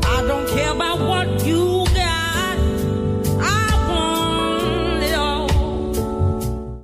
mystery_singer_short_clip.wav